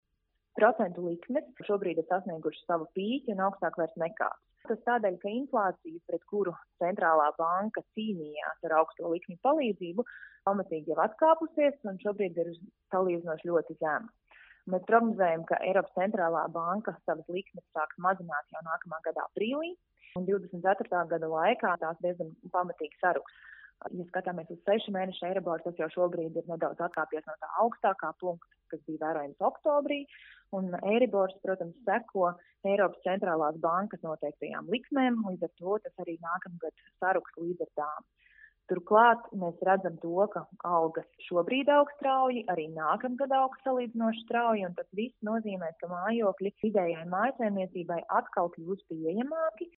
RADIO SKONTO Ziņās par mājokļu pieejamību nākamgad